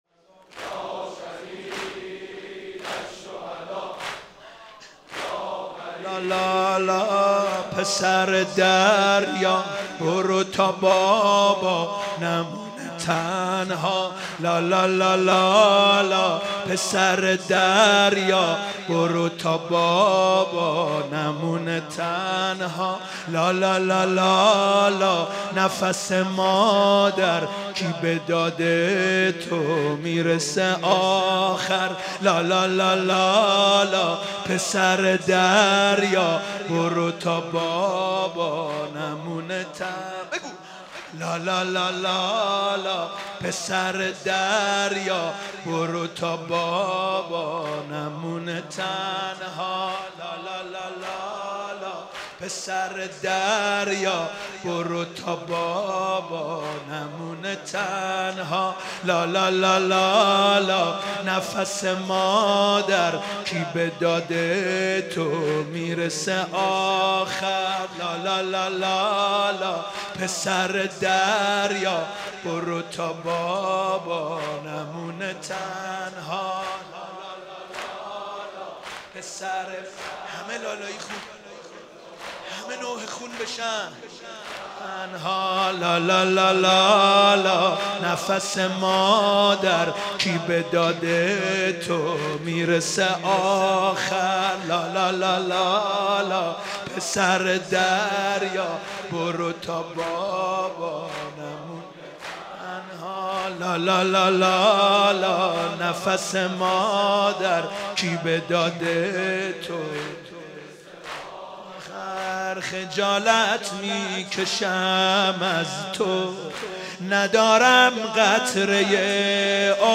شب هفتم محرم97 - مسجد امیر - زمینه - لالالالا پسر دریا